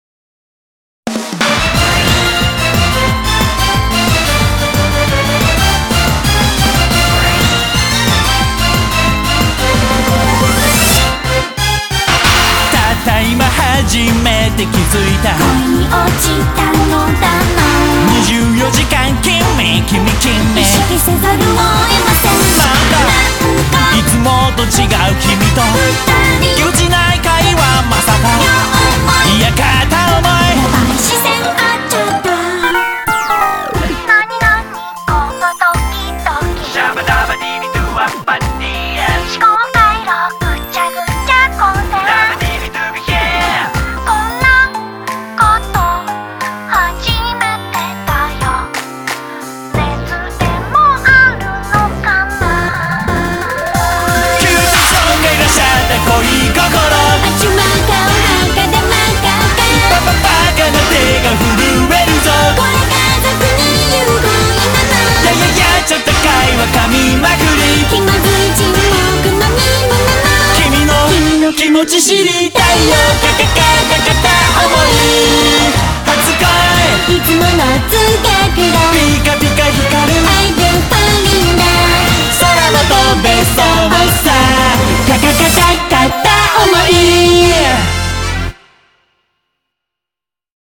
BPM90-180
Audio QualityPerfect (High Quality)
Yes, even the girl's vocals.